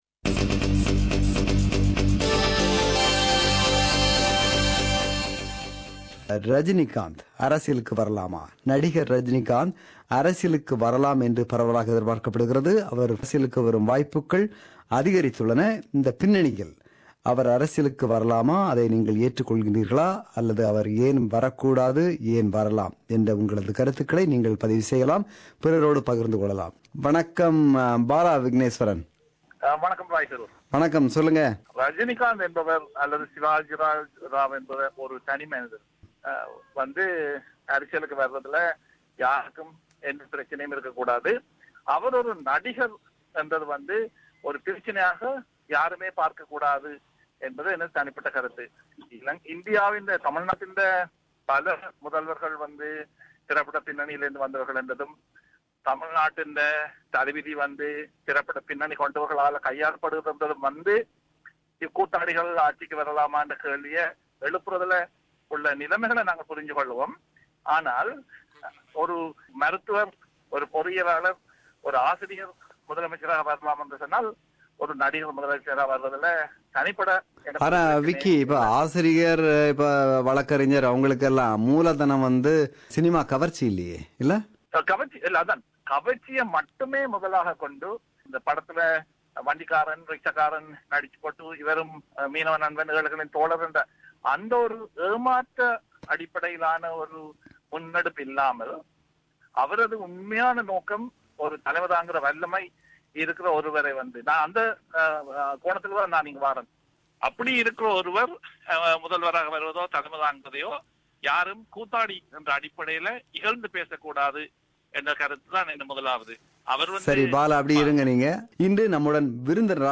This is the compilation of comments shared by our listeners in our Talkback program on 26 May.